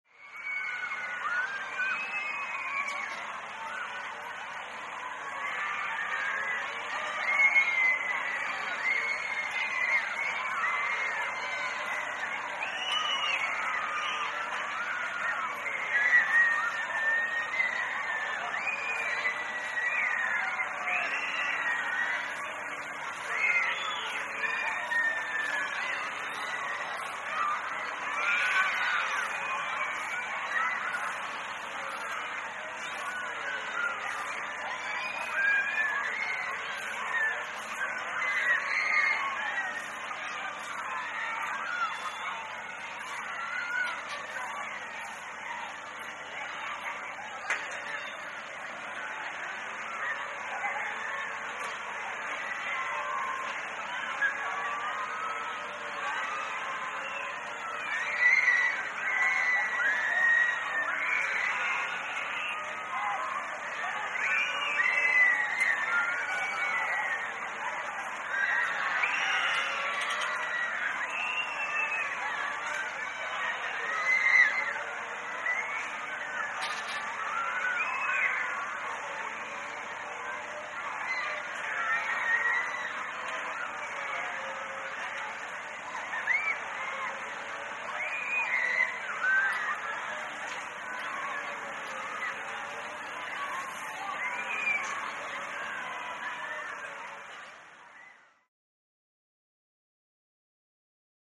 Ride; Carnival Ride Ambience With Distant Passenger Screams And Machinery.